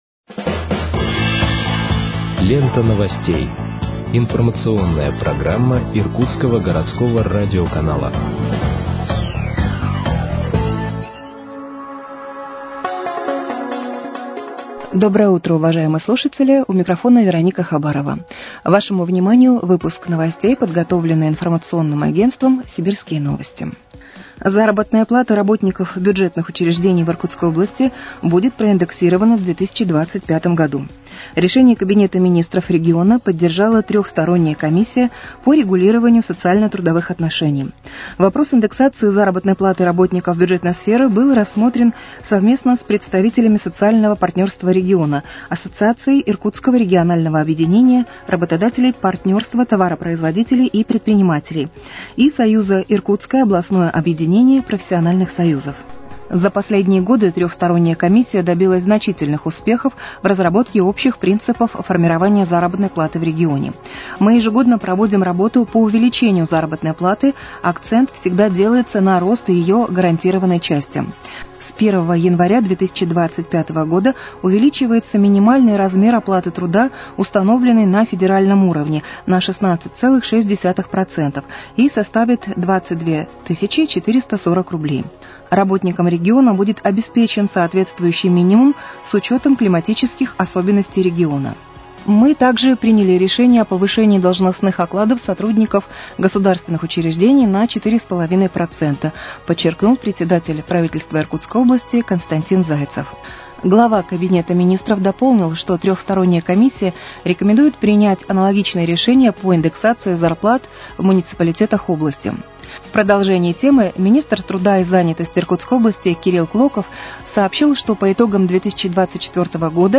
Выпуск новостей в подкастах газеты «Иркутск» от 26.12.2024 № 1